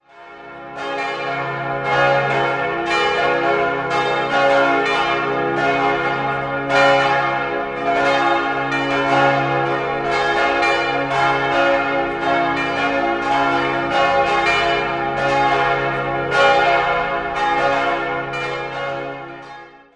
4-stimmiges Salve-Regina-Geläute: des'-f'-as'-b' Alle Glocken wurden 1974 von Rudolf Perner in Passau gegossen.